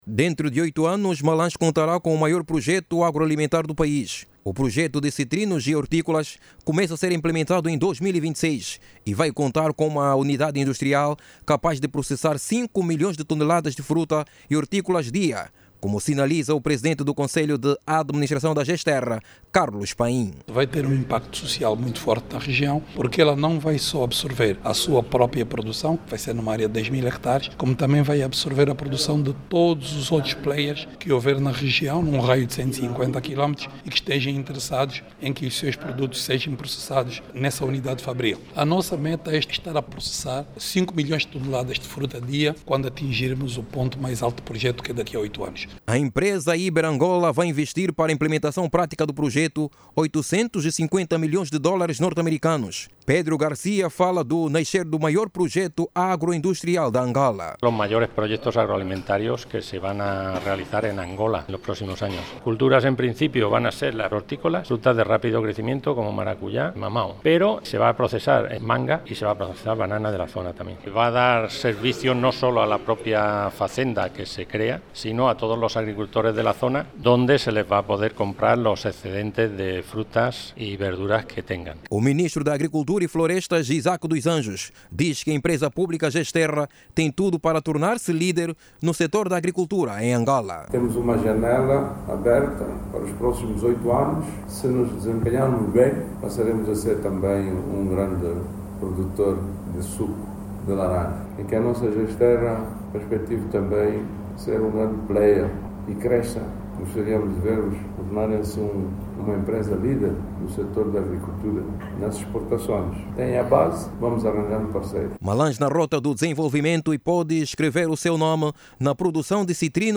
O projecto, que será desenvolvido numa área de 10 mil hectares no Pólo Agroindustrial de Capanda, em Malanje, será executado pelas empresas Iber Angola e Citrus Prime, sob supervisão da Gesterra. Jornalista